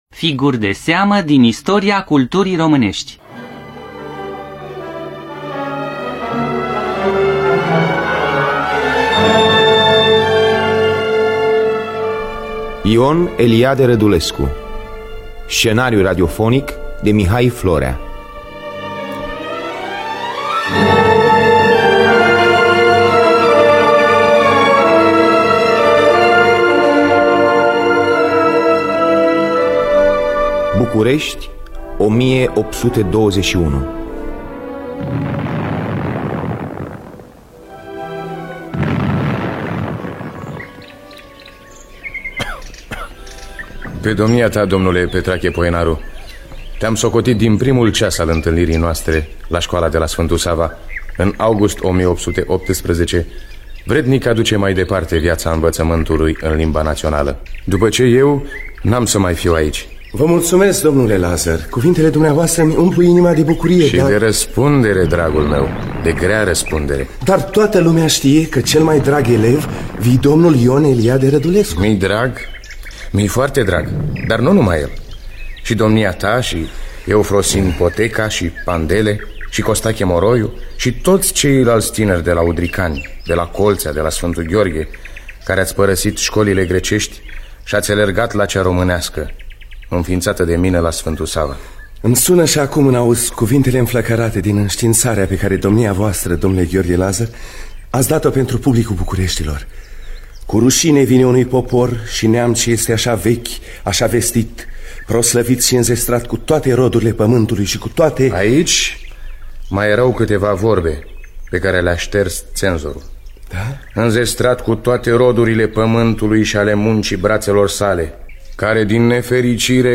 Scenariu radiofonic